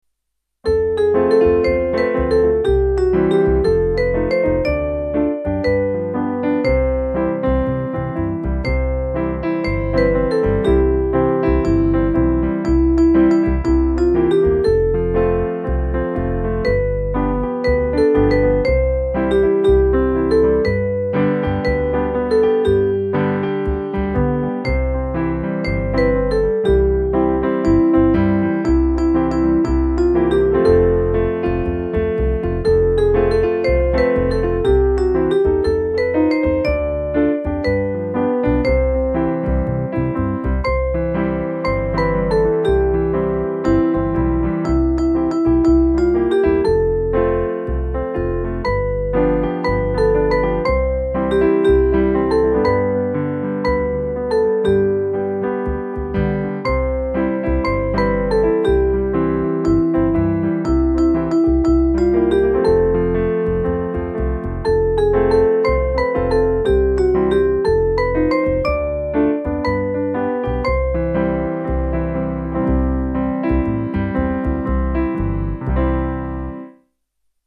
BIAB